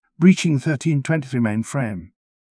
feat: add en-UK locale
breaching-1323-mainframe.wav